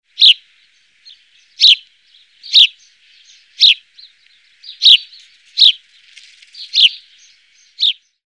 sperling-vogelstimmen.mp3